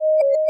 notifier_swipo.opus